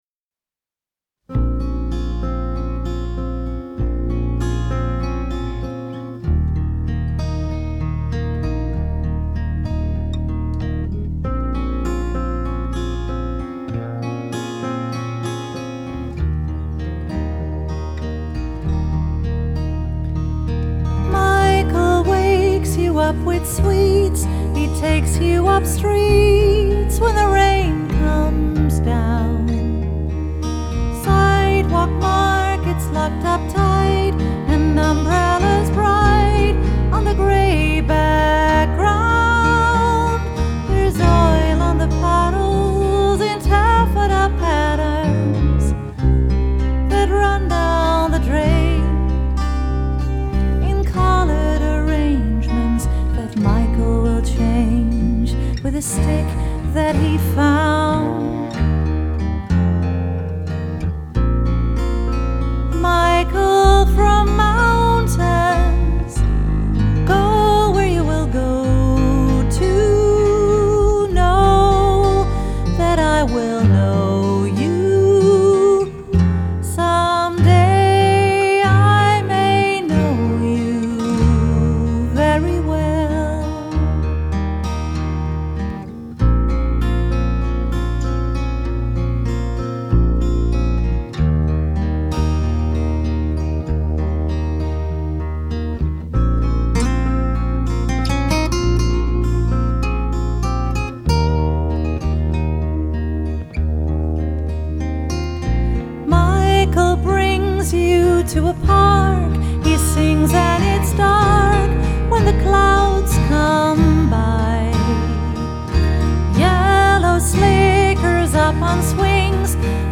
bassist
gitarist